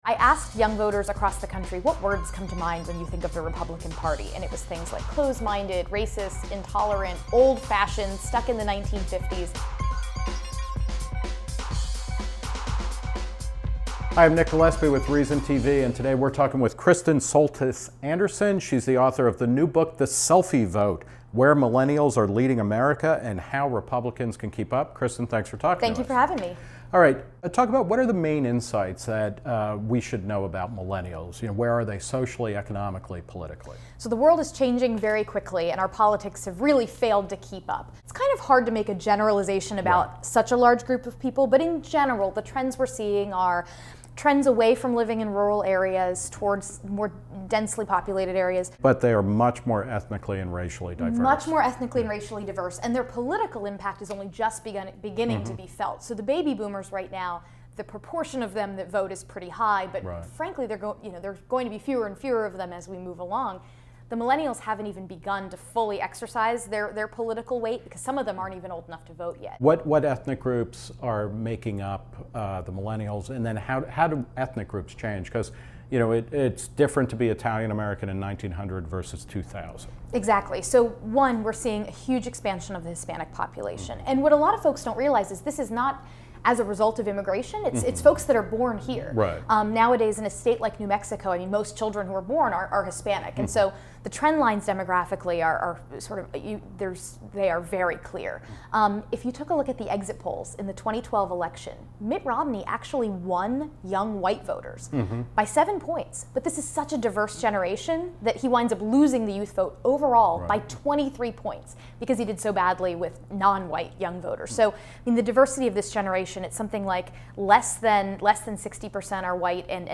The Republican pollster talks to Nick Gillespie about how to win the hearts and minds of a generation that has yet to realize its own political power.